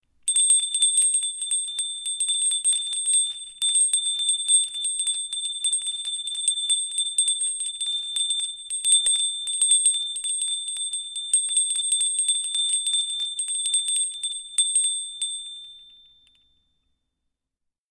دانلود صدای زنگوله 1 از ساعد نیوز با لینک مستقیم و کیفیت بالا
جلوه های صوتی